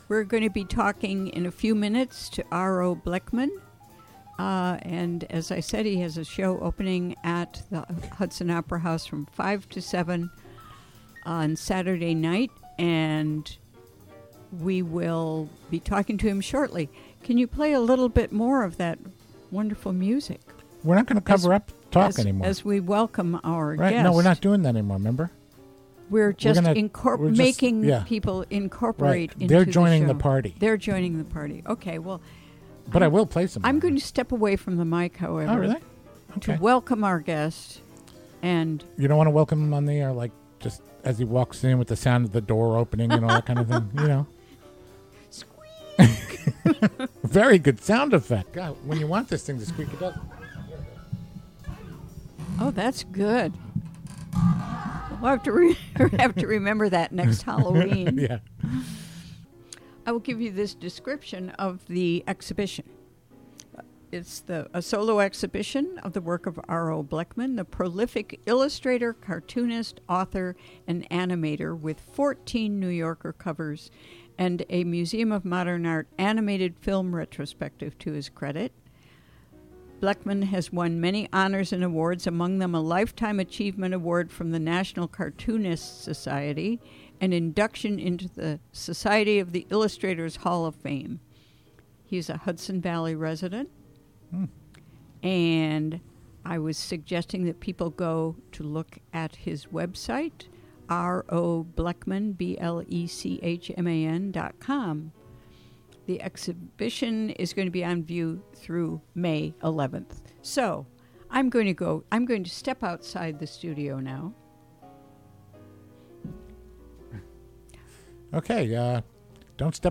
Celebrated cartoonist, author, animator, art director, blogger and Hudson Valley resident, R.O. Blechman will be in the Hudson studio to discuss the show of his work opening Sat., Mar. 29, at the Hudson Opera House.